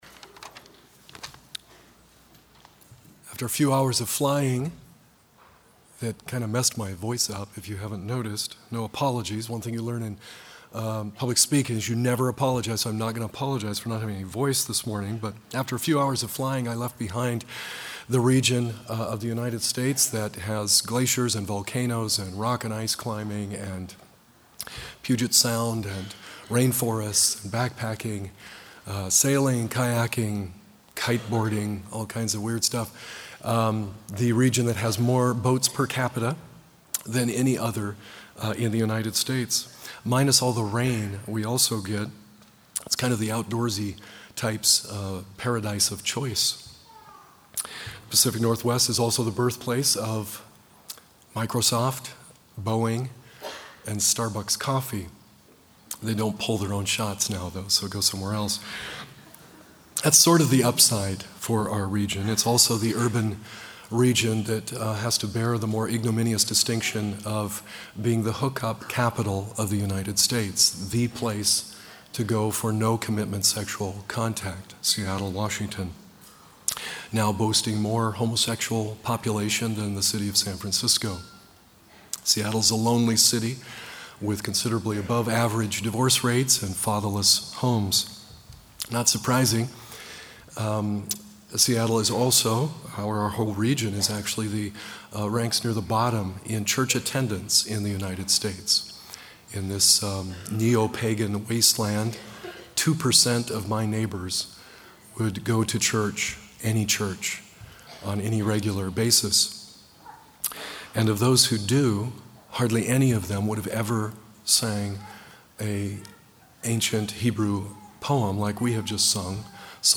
Chapel
Washington Address: Biblical Poetry in a Post-Biblical, Post-Poetry World Recording Date